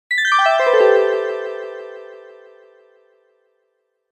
さらに、低めのピッチで鳴るため、静かな場所でも聞き取りやすいです。